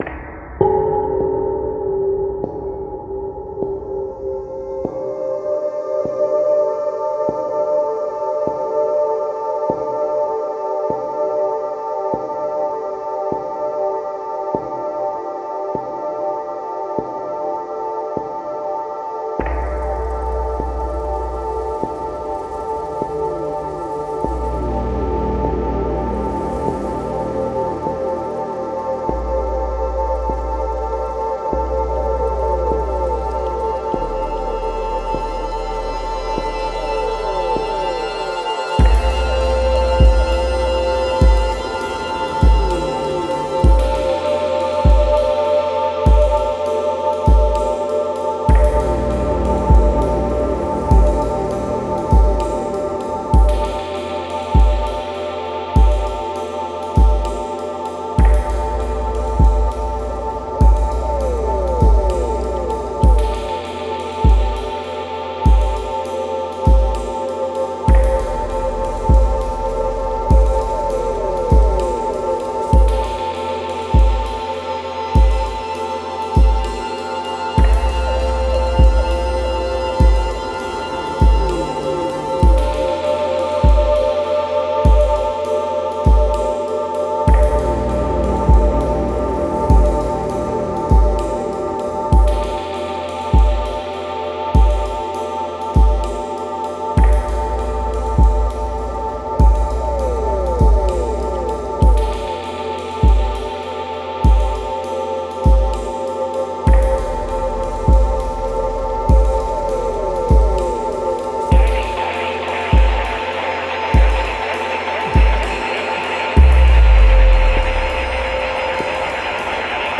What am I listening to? Genre: IDM.